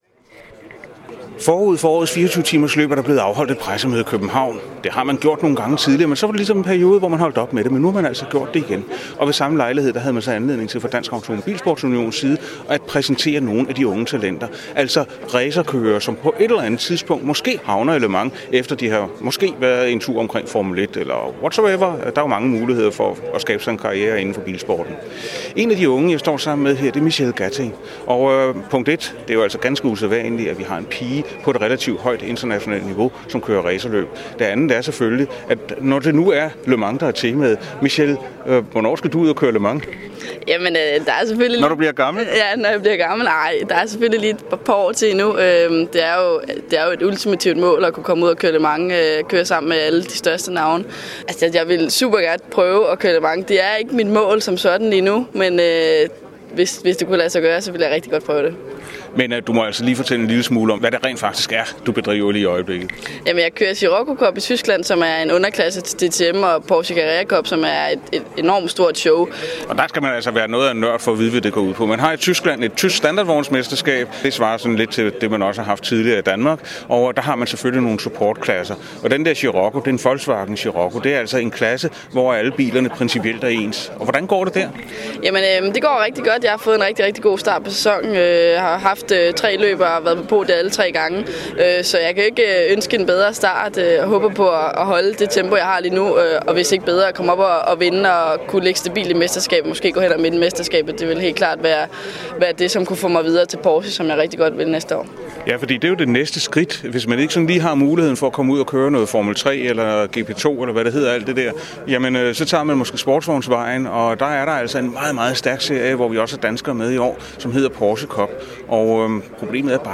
Interview i Motorradioen